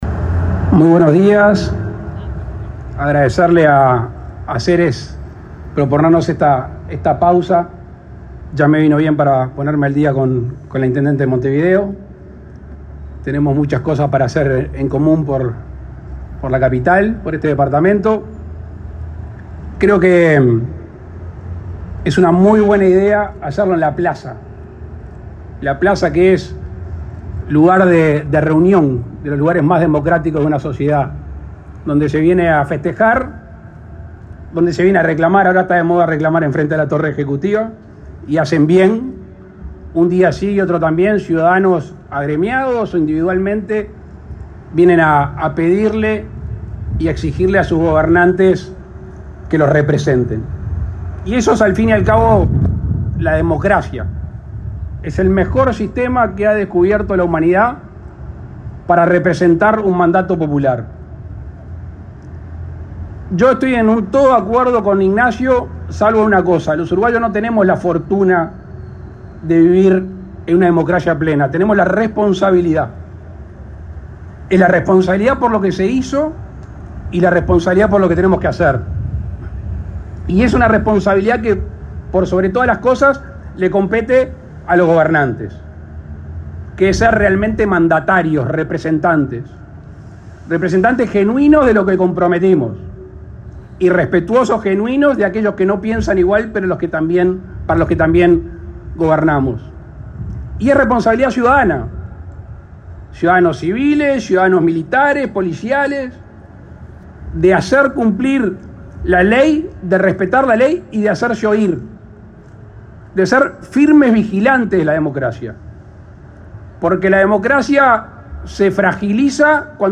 Oratoria del presidente de la República, Luis Lacalle Pou, en la inauguración, este 10 de setiembre, en la plaza Independencia, de la Expo Democracia,